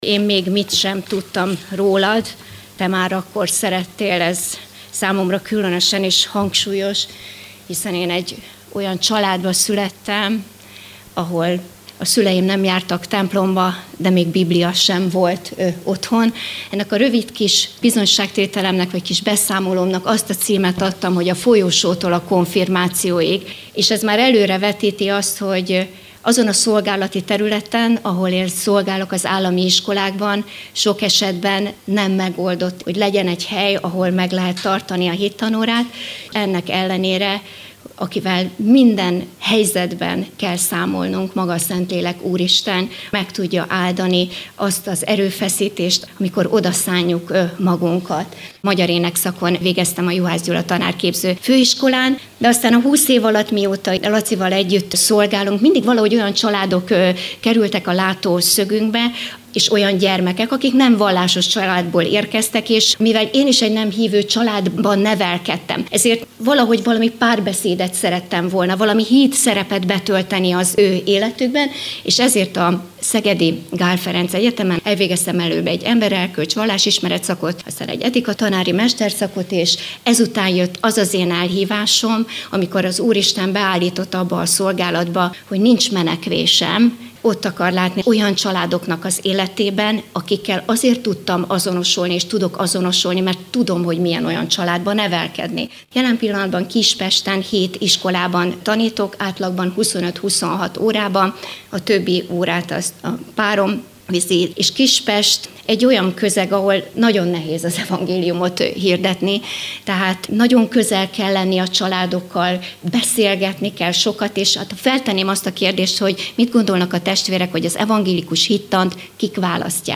Az Evangélikus Missziói Központ nyári missziói és munkatársképző konferenciájáról osztunk meg felvételeket a missziói percekben.